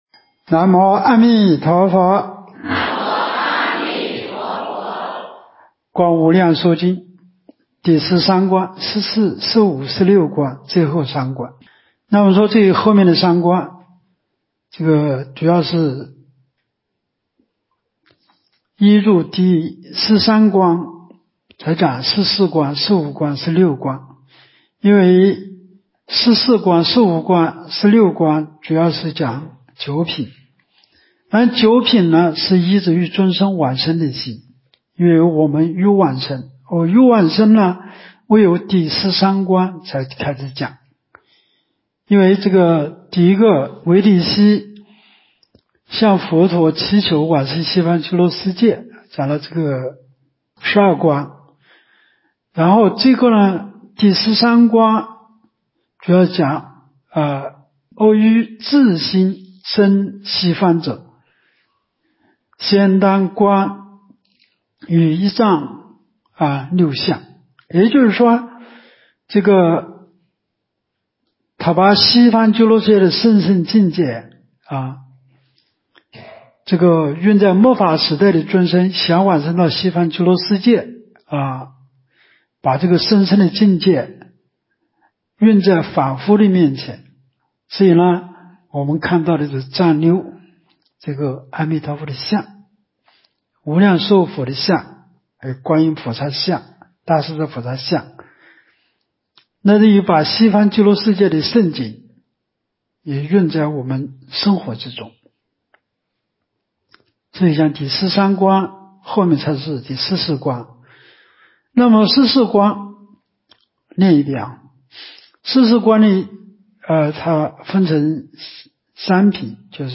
无量寿寺冬季极乐法会精进佛七开示（32）（观无量寿佛经）...